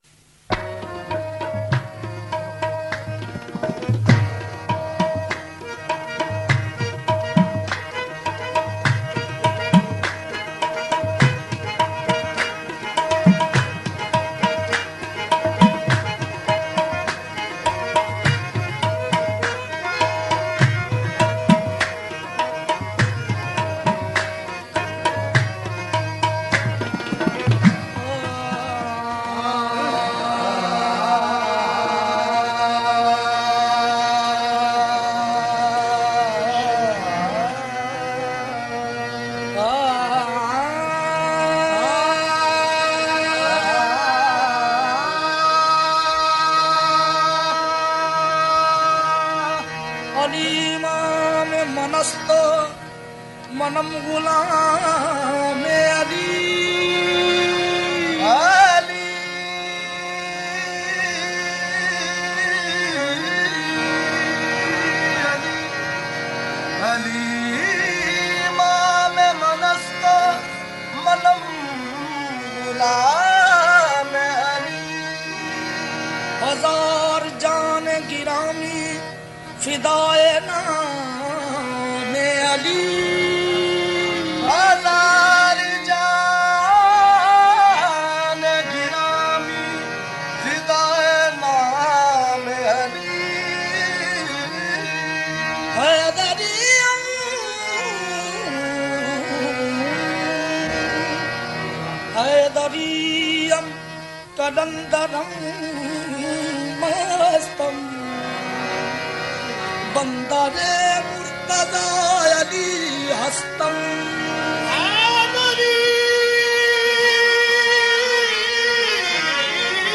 Milad un Nabi – Naat and Naatiya Kalam